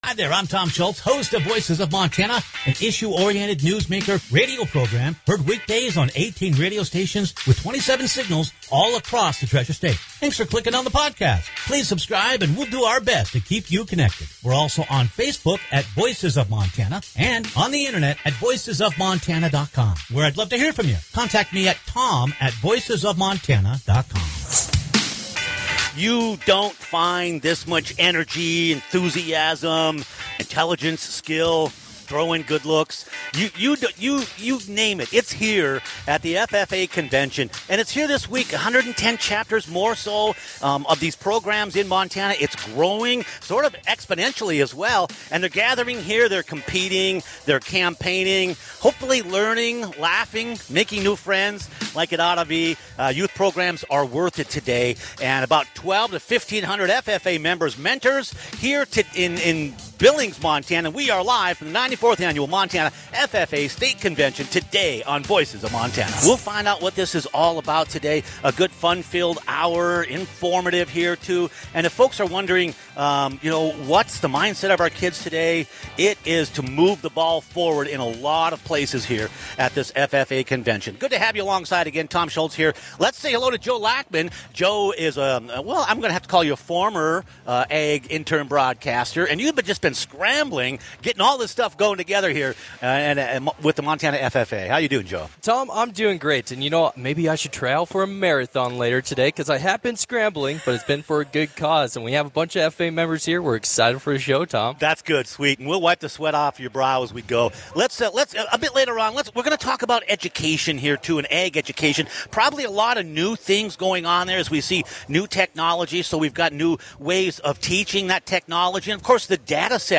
Click on the podcast for a fun-filled and informative hour broadcasting live from the 94th Annual Montana FFA Convention, where nearly 2,000 FFA members and mentors are gathered to compete, campaign, learn, laugh, and make new friends. We talk with several young FFA members and youth leaders about their experiences and what they see ahead